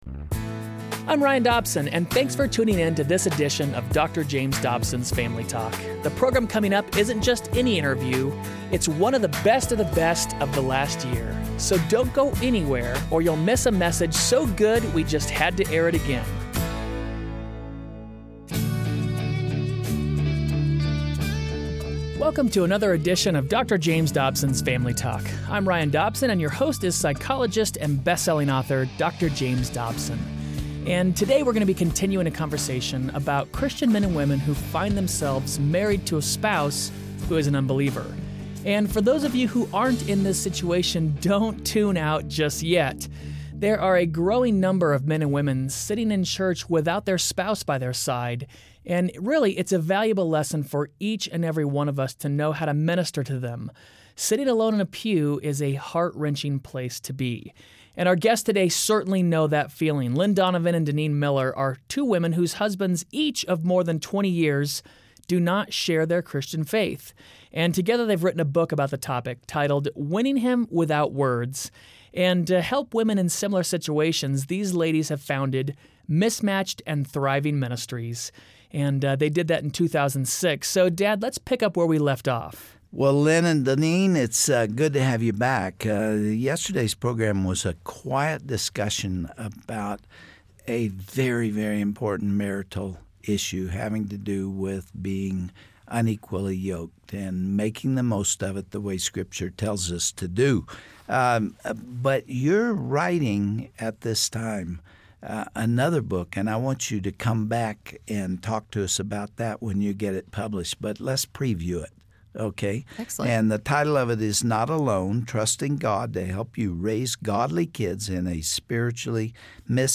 But what if he was still fast asleep, or lounging on the couch in pajamas? Hear women share about the struggles of raising kids in an unequally yoked marriage.